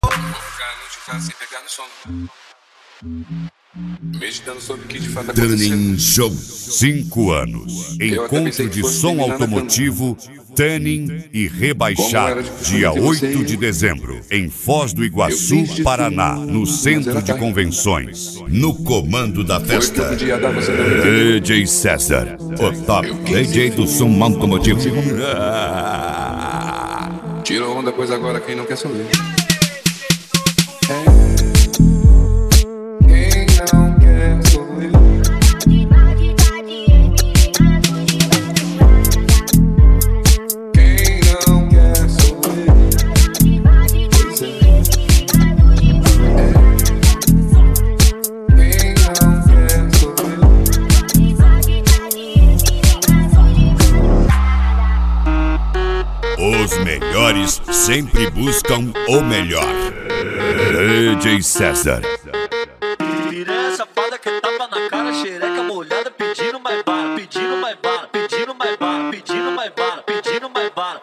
Mega Funk
Racha De Som